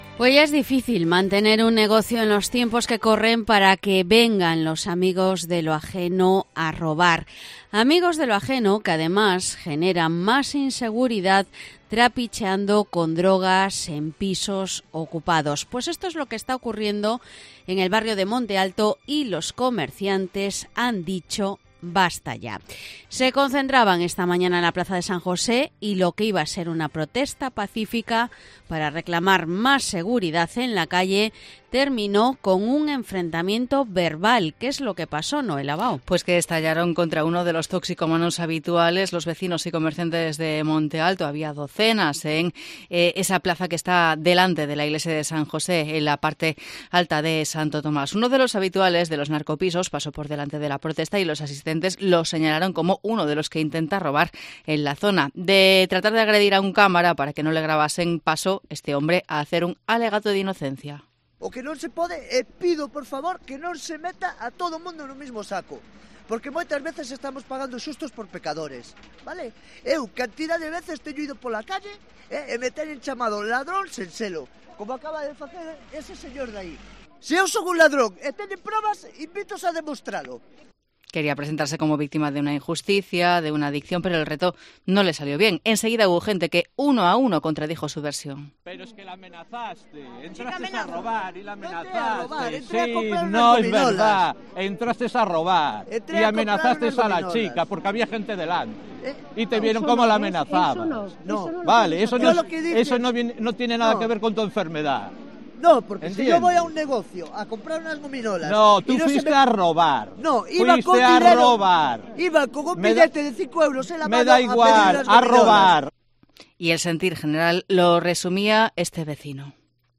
Crónica de la tensa concentración en Monte Alto